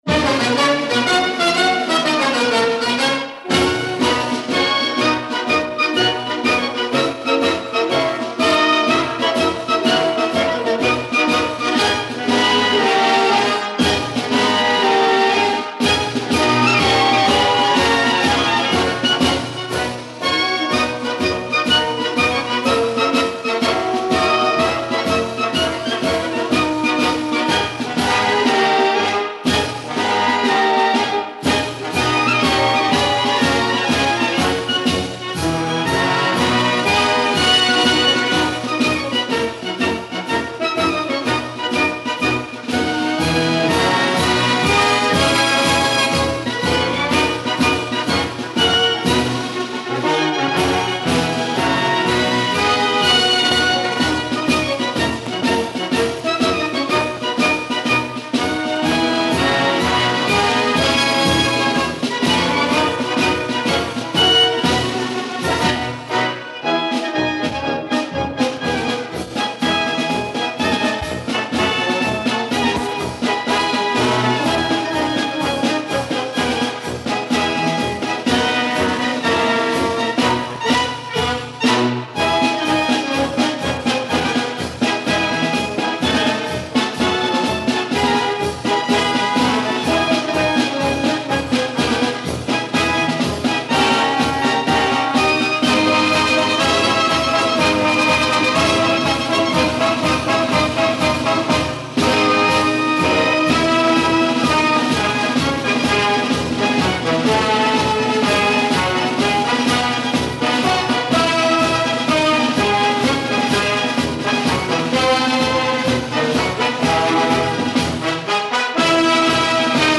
Here's a little Americana for your Independence Day celebration, in case you want to stage a neighborhood parade but don't have a marching band readily available - John Philips Sousa marches. Sorry, the last track has a skip that even the most extreme measures (I'm talking razor blade, here) couldn't remove. The rest of it was pretty beat up, as well, but after several hours of auto and manual de-clicking, it's at least listenable.